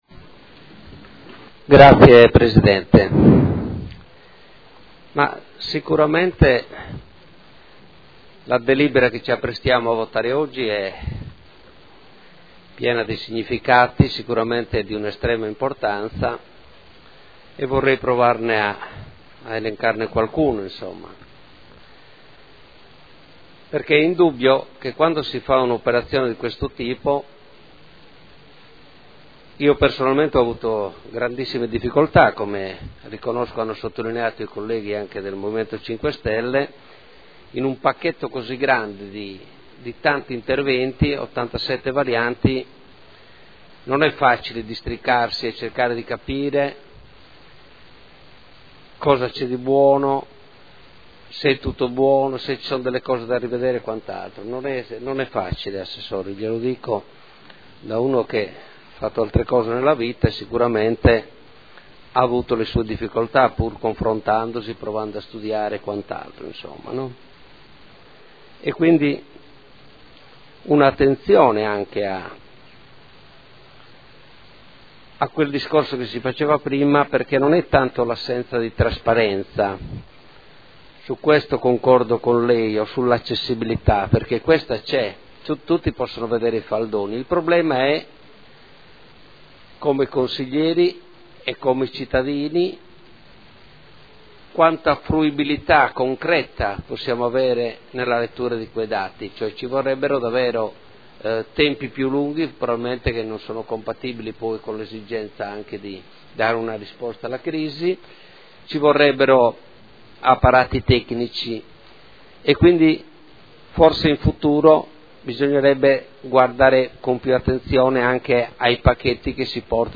Marco Cugusi — Sito Audio Consiglio Comunale